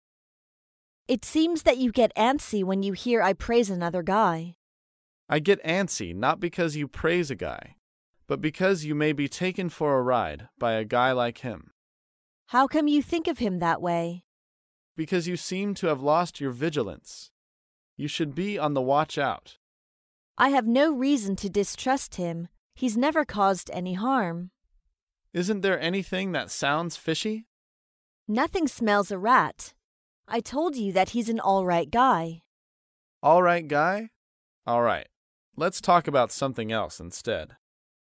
在线英语听力室高频英语口语对话 第154期:情感话题的听力文件下载,《高频英语口语对话》栏目包含了日常生活中经常使用的英语情景对话，是学习英语口语，能够帮助英语爱好者在听英语对话的过程中，积累英语口语习语知识，提高英语听说水平，并通过栏目中的中英文字幕和音频MP3文件，提高英语语感。